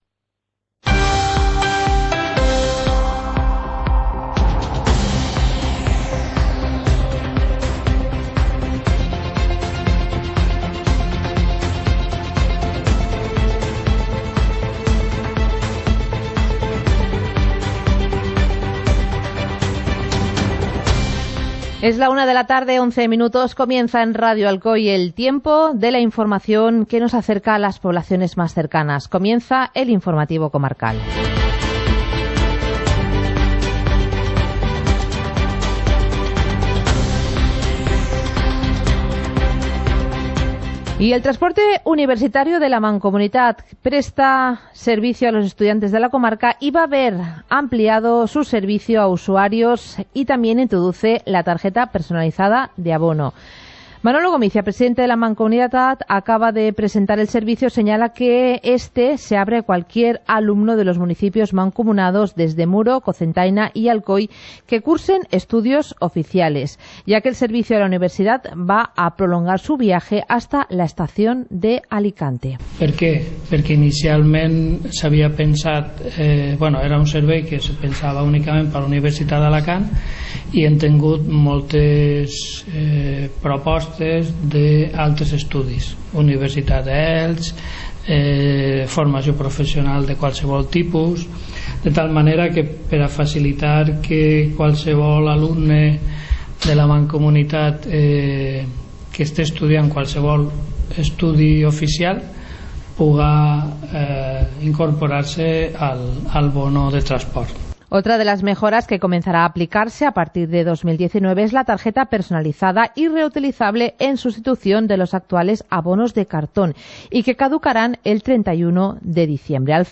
Informativo comarcal - martes, 04 de diciembre de 2018